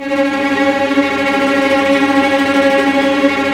Index of /90_sSampleCDs/Roland - String Master Series/STR_Vcs Tremolo/STR_Vcs Trem f